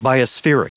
Prononciation du mot biospheric en anglais (fichier audio)
Prononciation du mot : biospheric